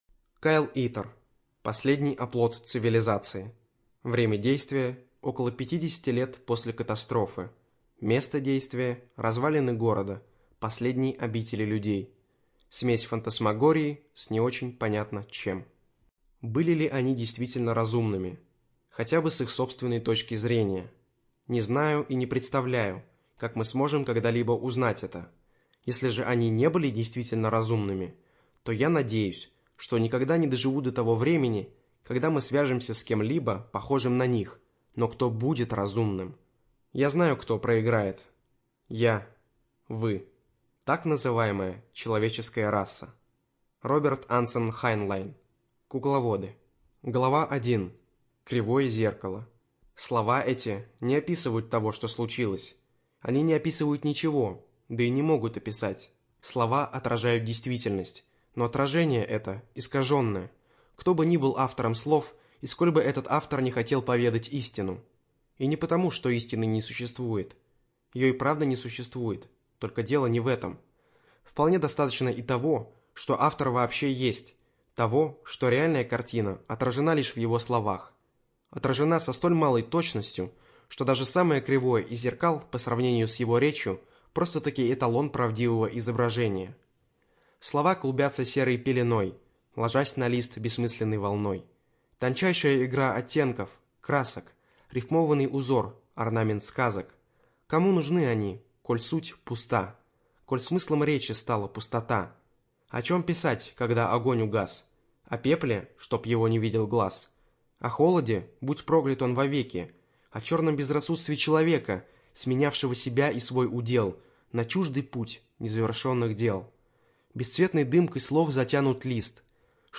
ЖанрФантастика